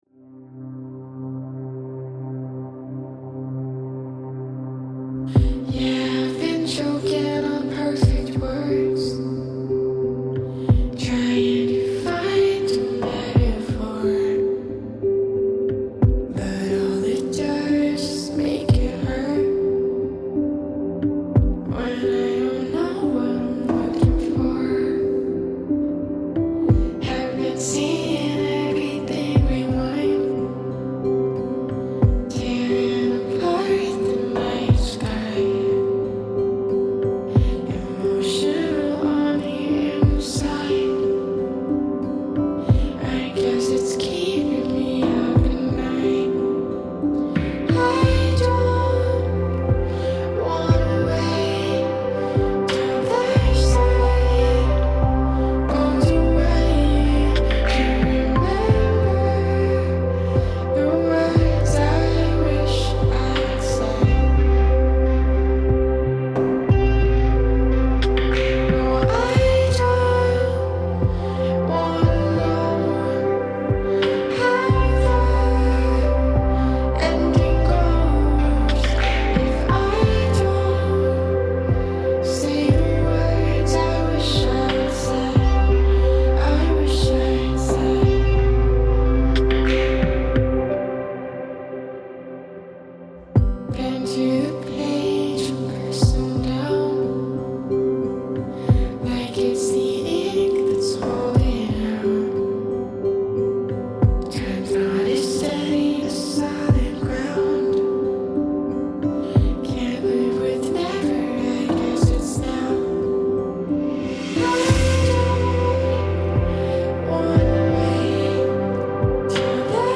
Genre: pop, indie, singer/songwriter, ambient
Tags: female vocalist, acoustic guitar